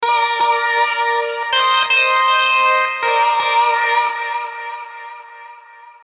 标签： 80 bpm Folk Loops Guitar Electric Loops 1.01 MB wav Key : Unknown
声道立体声